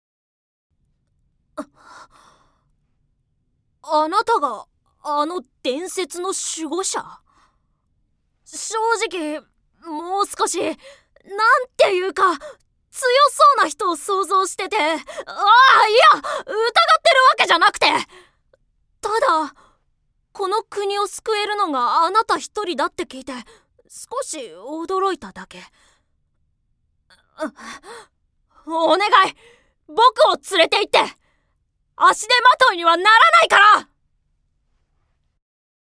◆少年◆